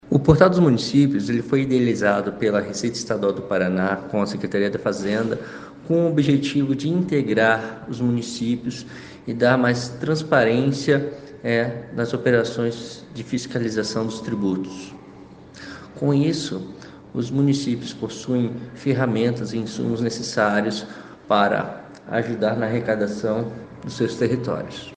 Sonora do auditor fiscal da Receita Estadual